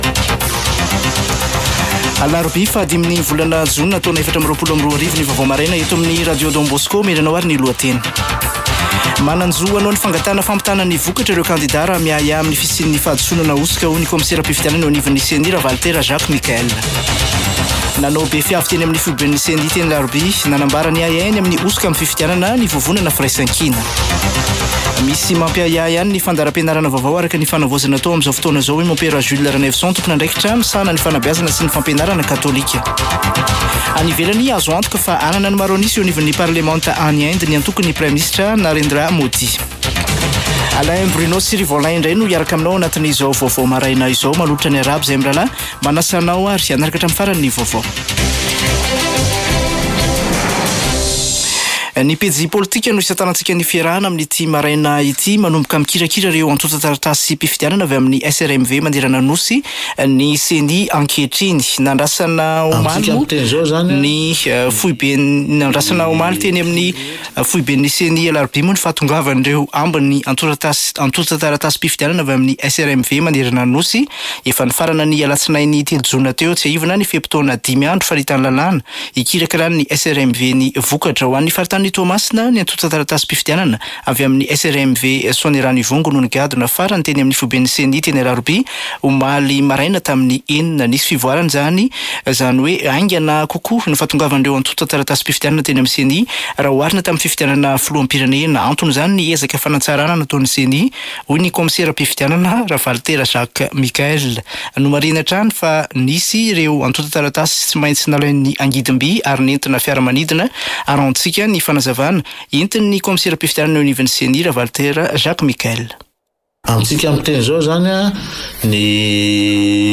[Vaovao maraina] Alarobia 05 jona 2024